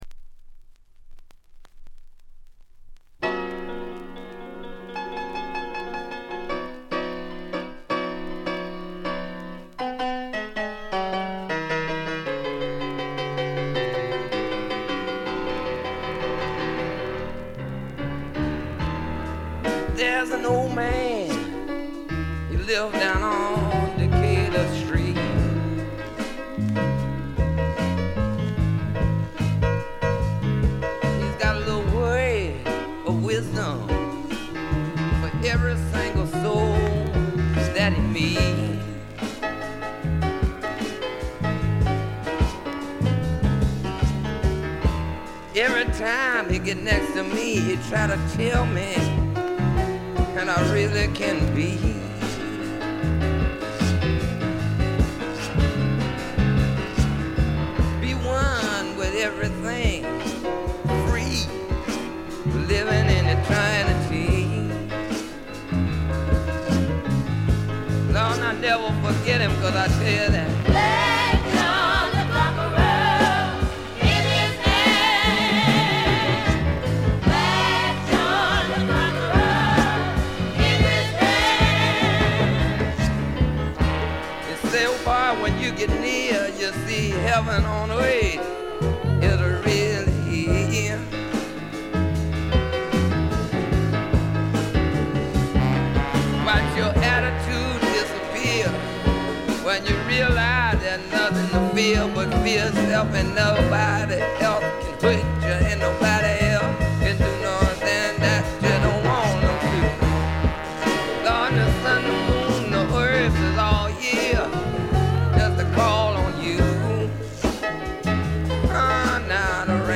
静音部での軽微なバックグラウンドノイズ、チリプチ。散発的なプツ音2-3回。
ヴードゥー・サイケ最後の名盤！
試聴曲は現品からの取り込み音源です。